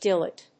/díːlít(米国英語)/